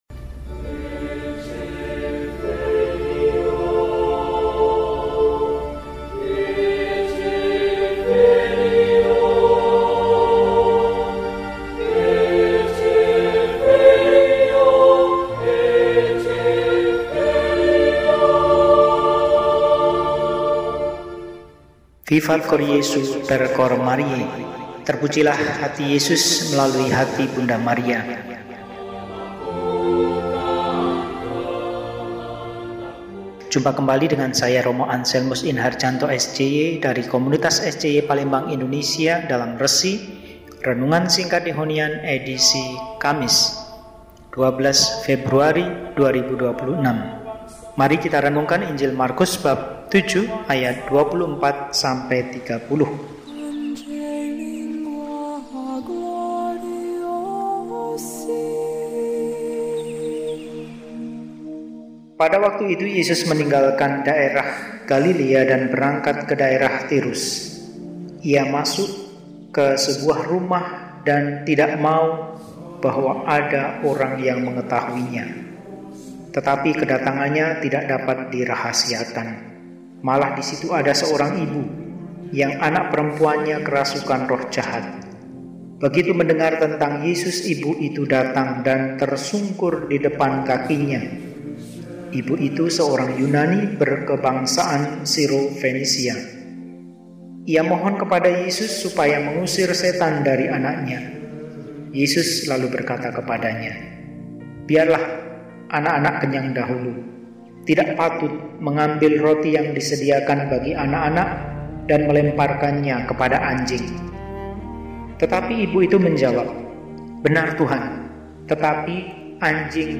Kamis, 12 Februari 2026 – Hari Biasa Pekan V – RESI (Renungan Singkat) DEHONIAN